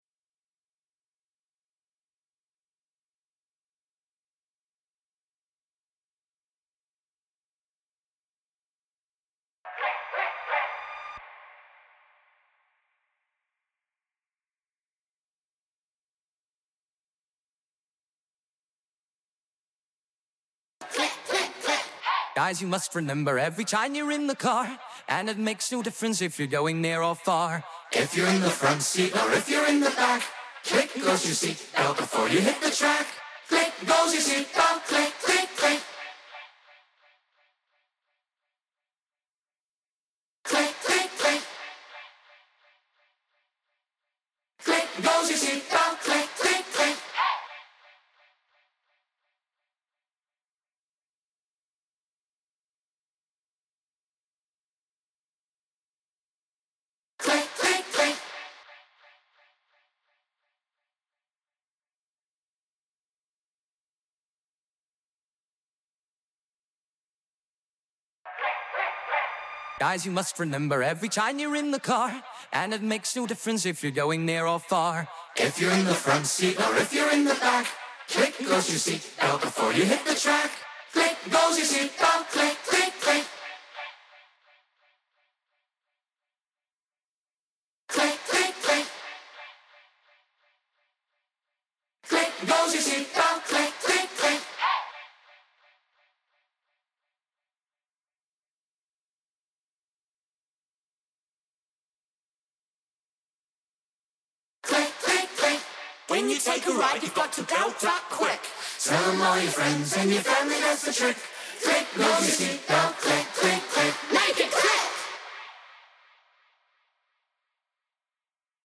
Remix Vocals: